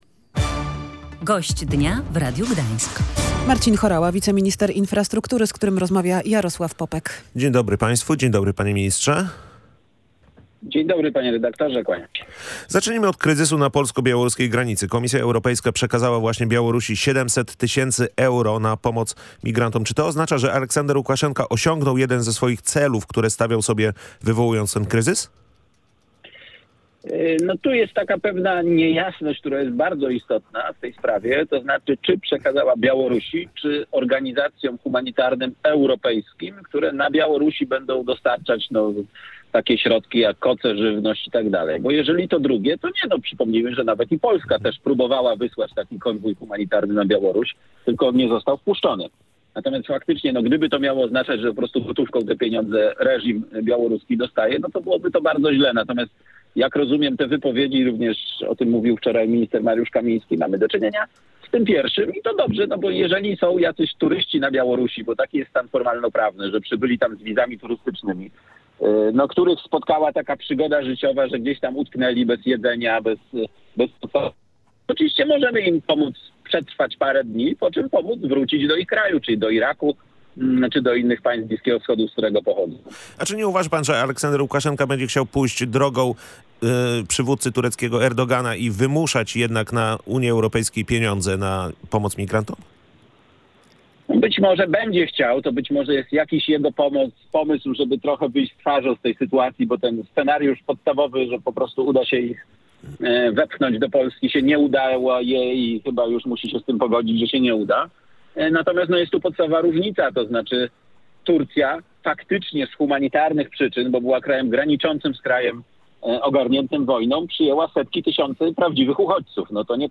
w rozmowie z Gościem Dnia Radia Gdańsk, którym był wiceminister infrastruktury Marcin Horała.